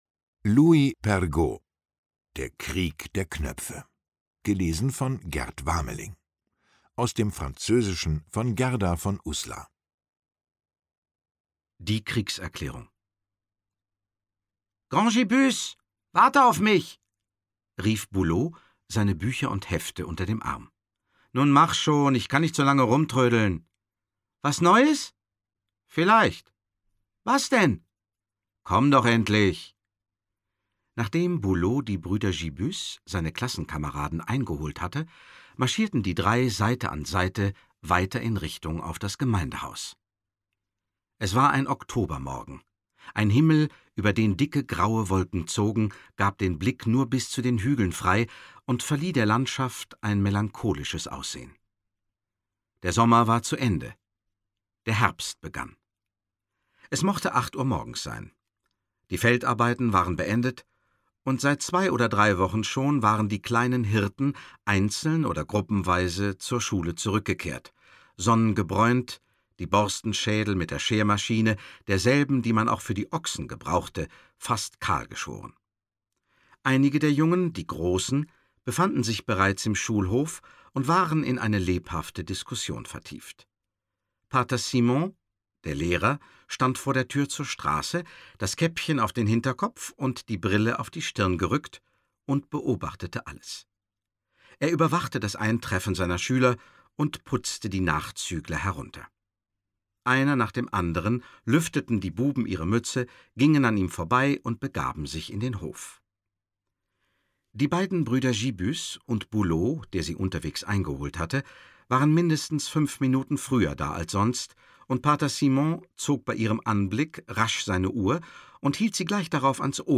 Lesung mit Gerd Wameling (1 mp3-CD)
Gerd Wameling (Sprecher)